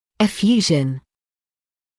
[ɪ’fjuːʒn][и’фйуːжн]выпот; излияние, истечение